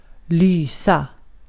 Ääntäminen
IPA : /biːm/ US : IPA : [biːm]